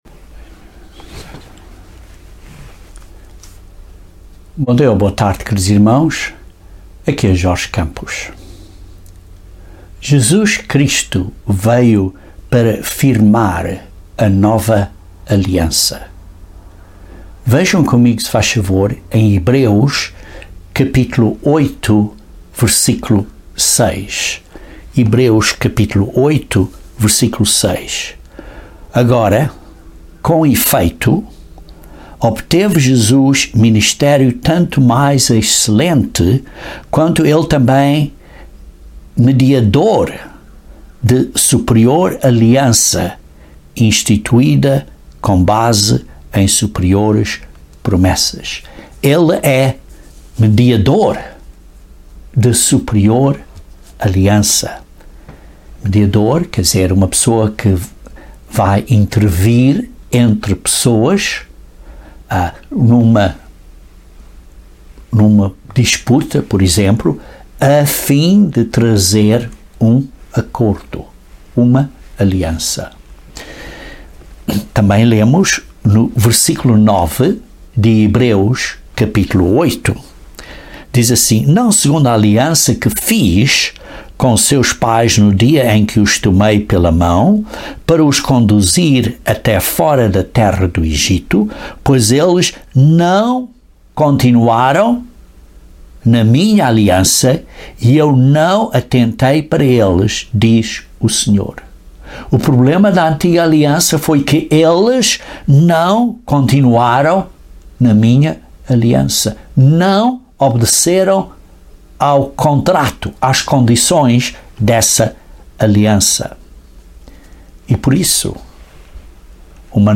Este sermão explica o significado da profecia das setenta semanas de Daniel 9 e como dá detalhes da firmação da Nova Aliança. Também menciona quando será completada e a nossa responsibilidade.